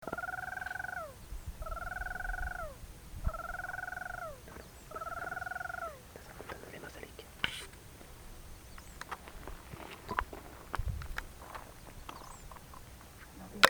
Ash-throated Crake (Mustelirallus albicollis)
Life Stage: Adult
Province / Department: Corrientes
Location or protected area: Ruta 6 (entre Concepcion y Mburucuya)
Condition: Wild
Certainty: Observed, Recorded vocal